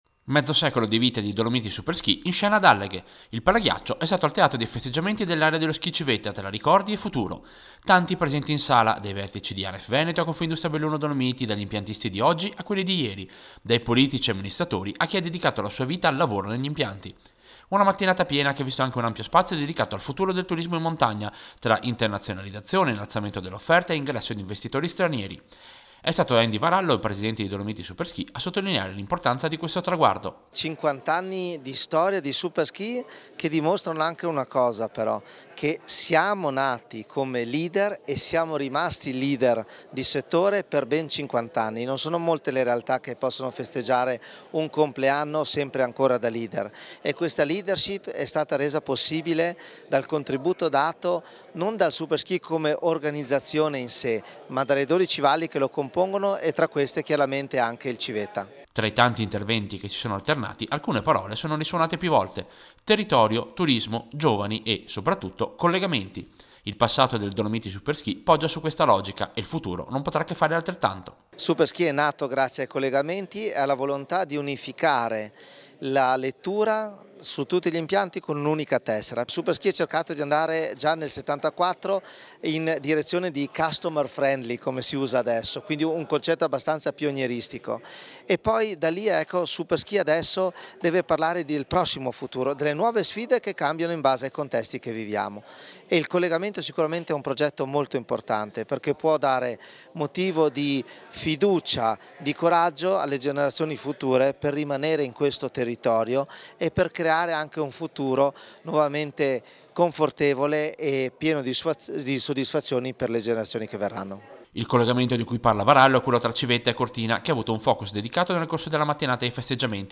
LA DIRETTA RADIO PIU’